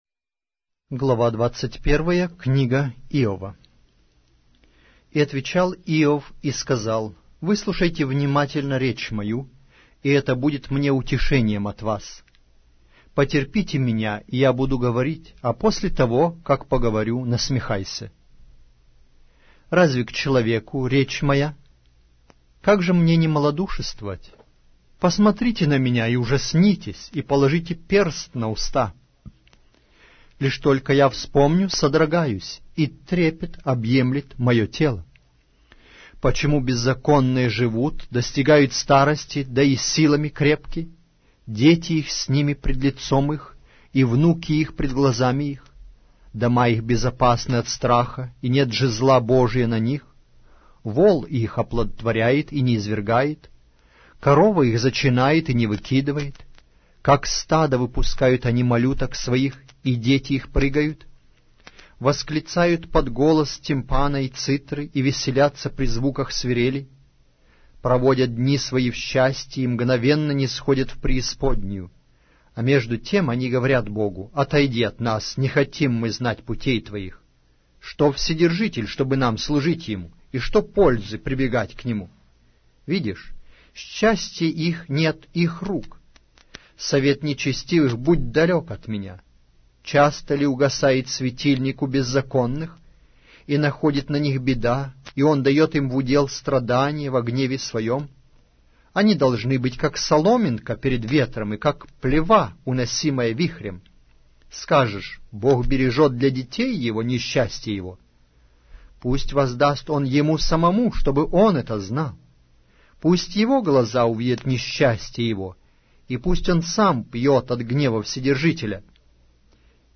Аудиокнига: Праведный Иов